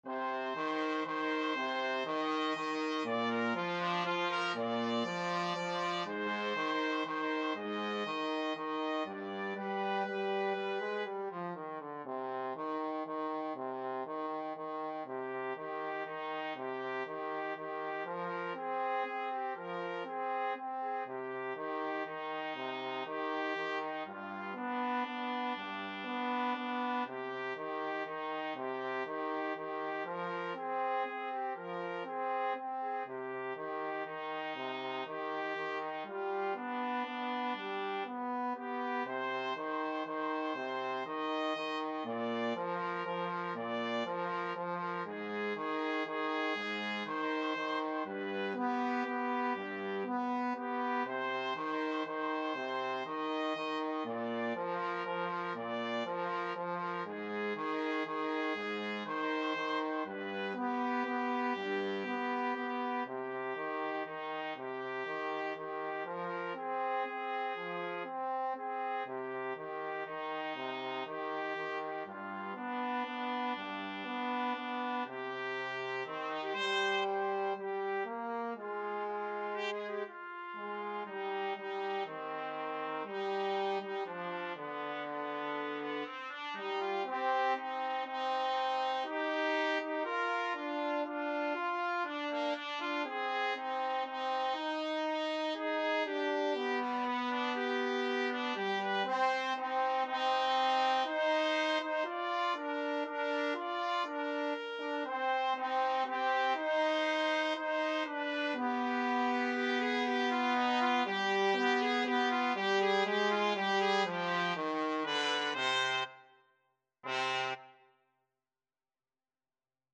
3/4 (View more 3/4 Music)
Slow Waltz .=40